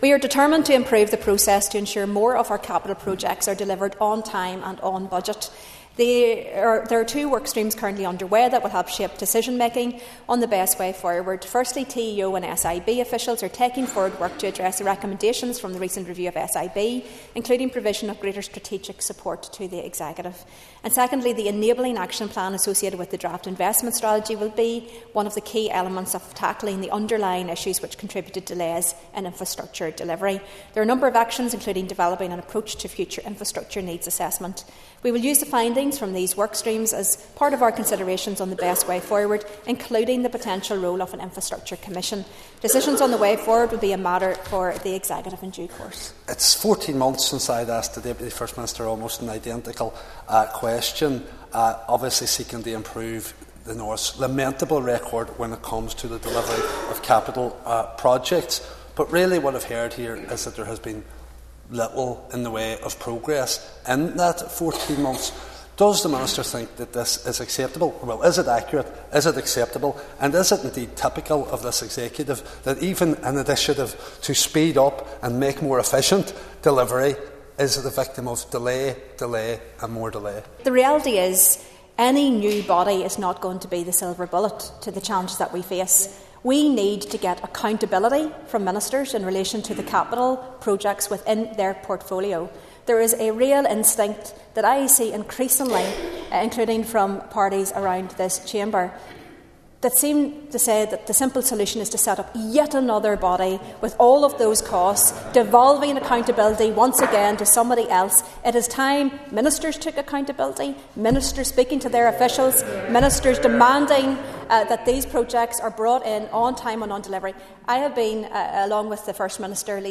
Speaking during questions to Emma Little-Pengelly, Mark Durkan said such a commission is widely regarded as essential to ensuring major projects such as the A5 are delivered on time and within budget.
However, Mr Durkan told the Assembly there’s been no progress since he asked the same question early last year…………